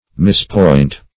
Mispoint \Mis*point"\, v. t. To point improperly; to punctuate wrongly.